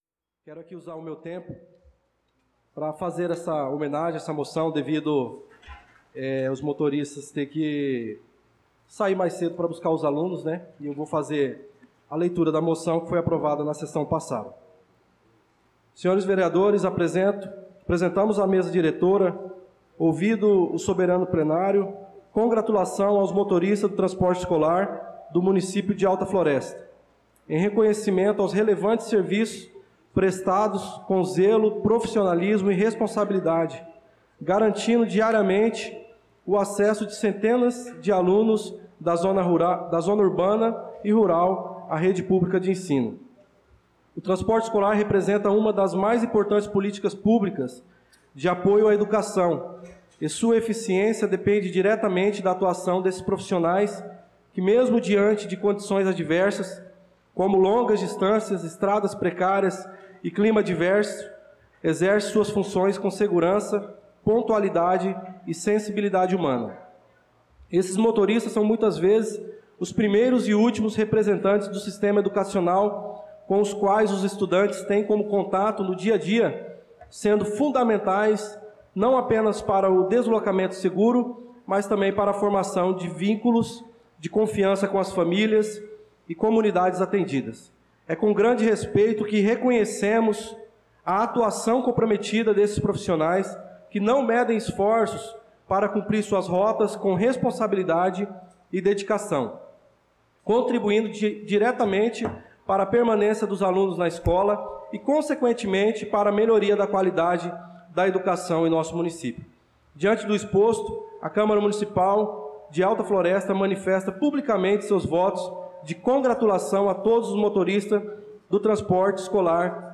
Pronunciamento do vereador Darlan Carvalho na Sessão Ordinária do dia 23/06/2025